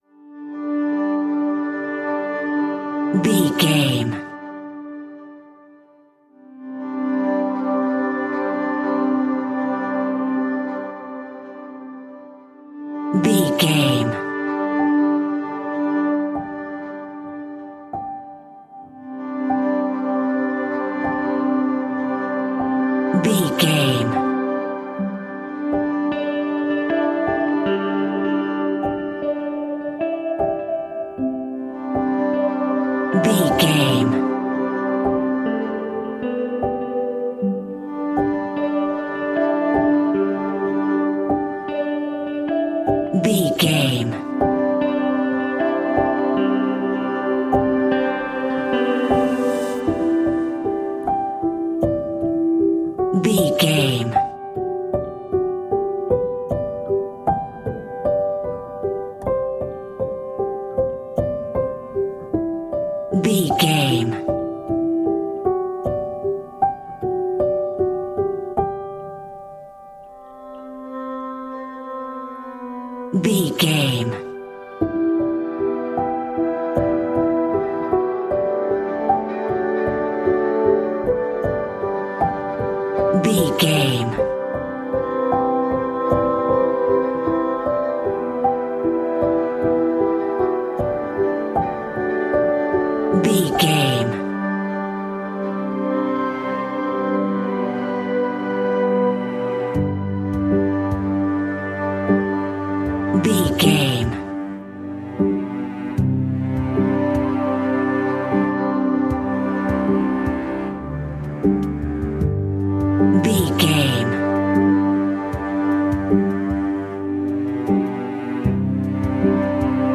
In-crescendo
Ionian/Major
Slow
contemplative
dreamy
tranquil
melancholy
mellow
cello
electric guitar
piano
strings